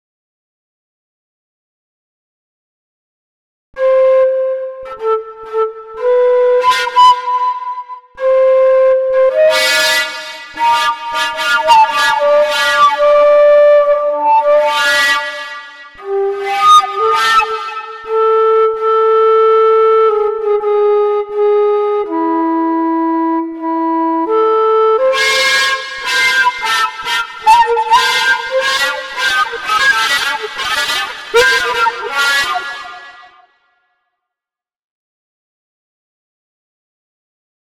- synthèse sonore de
flûte, nécessité d'avoir bien compris tous les phénomènes physiques en jeux (simulateur en temps réel).